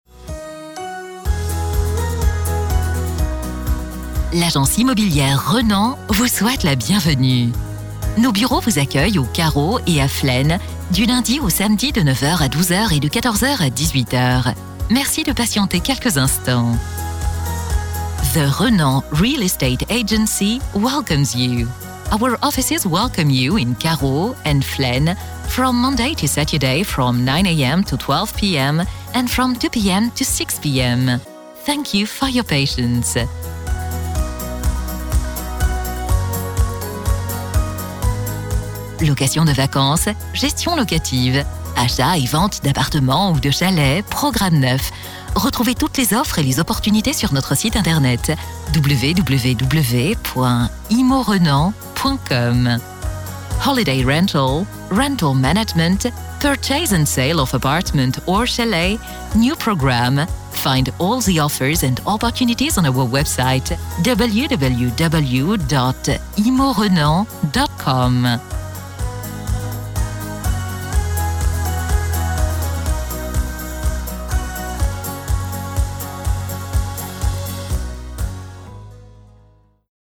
L’enregistrement de vos messages est réalisé par des professionnels dans notre studio d’enregistrement.
Prédécroché en agence